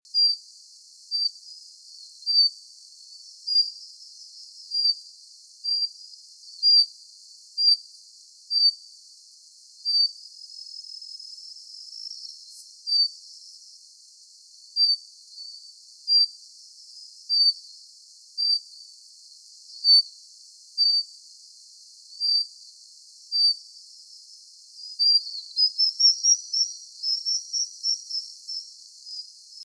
57-2杉林溪2013鉛色水鶇雄扇尾.mp3
物種名稱 鉛色水鴝 Rhyacornis fuliginosa affinis
錄音地點 南投縣 鹿谷鄉 杉林溪
錄音環境 溪邊
行為描述 雄鳥扇尾
錄音: 廠牌 Denon Portable IC Recorder 型號 DN-F20R 收音: 廠牌 Sennheiser 型號 ME 67